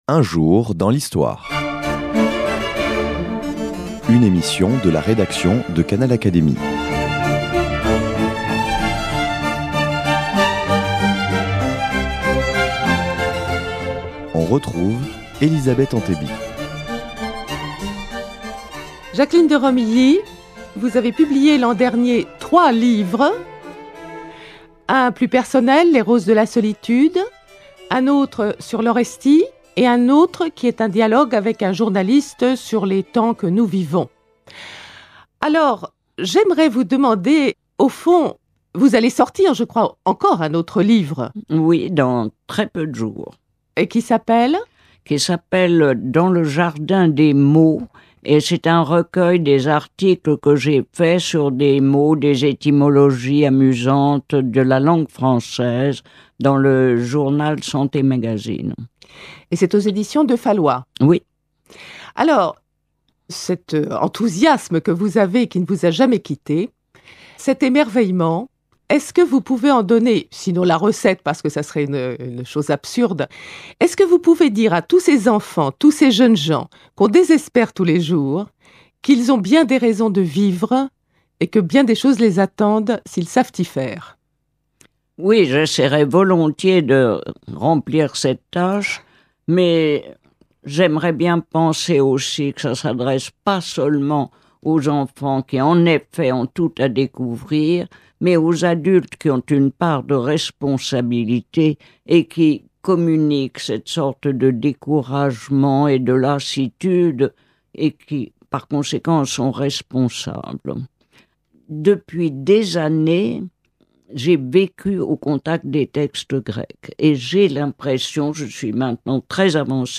C’est en tant qu’helléniste que Jacqueline de Romilly s’exprime ici sur l’Europe. Elle rappelle que la démocratie est née en Grèce mais aussi que plusieurs grands auteurs grecs ont critiqué cette conception.